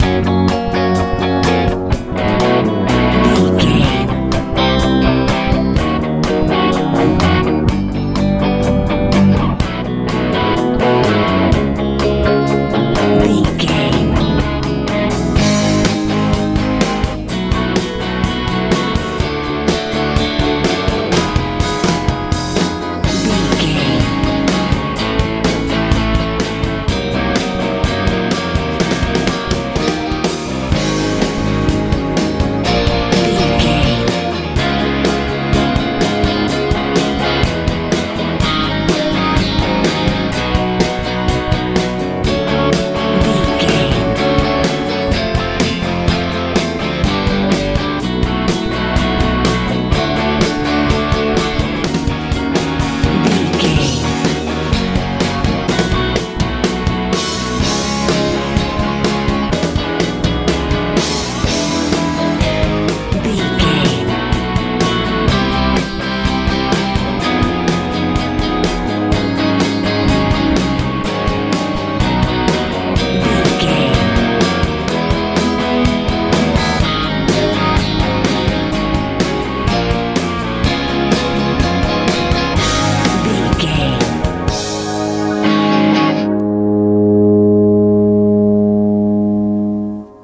high school rock feel
Ionian/Major
F♯
joyful
happy
electric guitar
bass guitar
drums
synthesiser
80s
90s